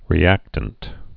(rē-ăktənt)